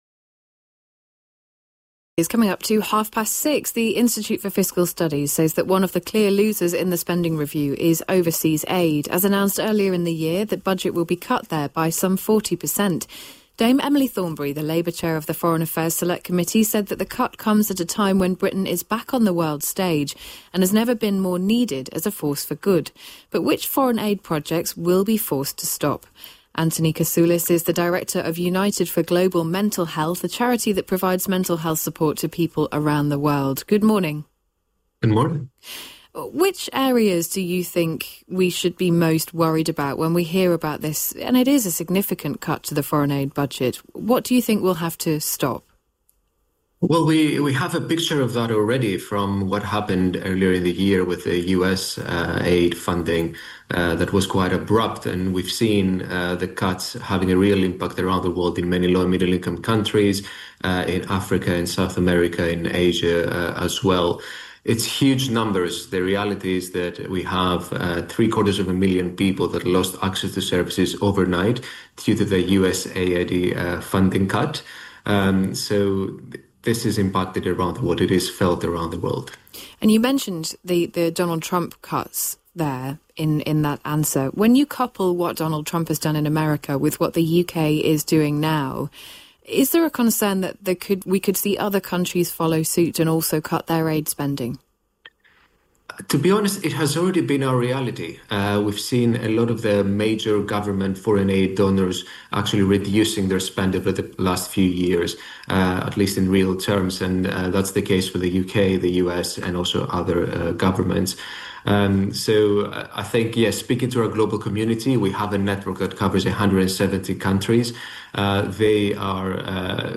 Funding Cuts: Times Radio interviews